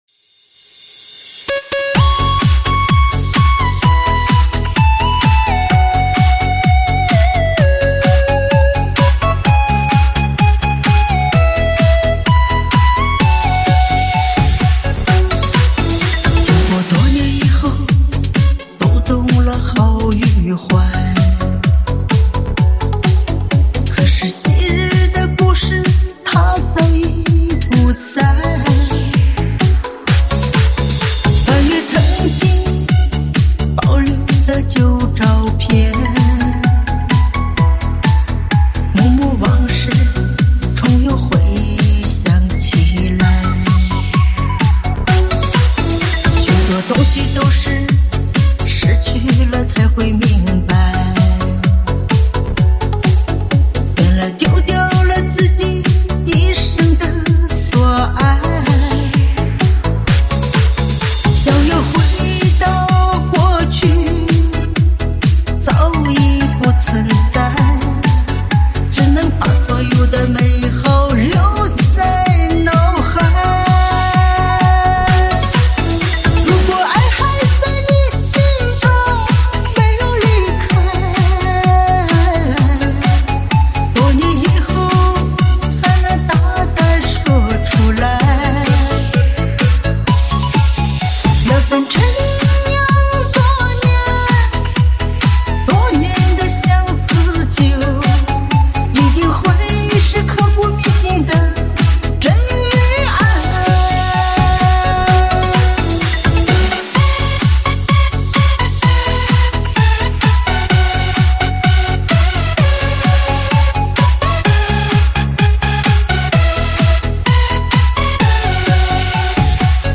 音质不错